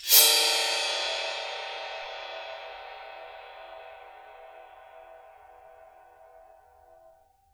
susCymb1-scrape1_v1.wav